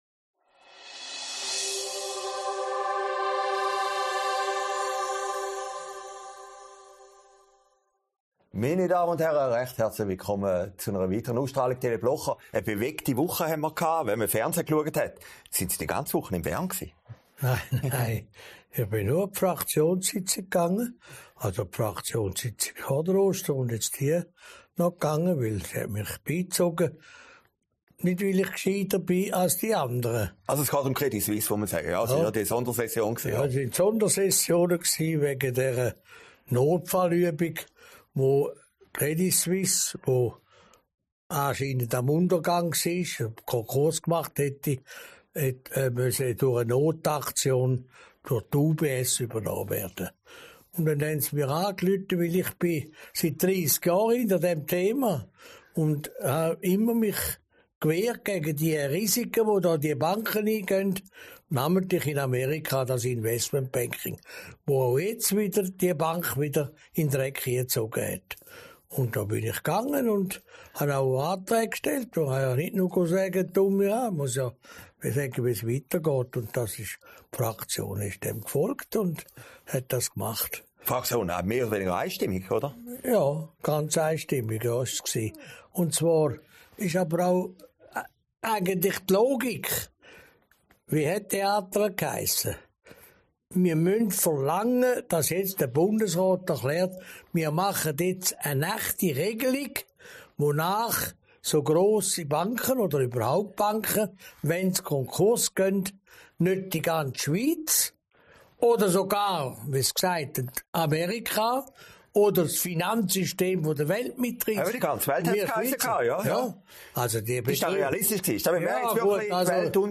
Sendung vom 14. April 2023, aufgezeichnet in Herrliberg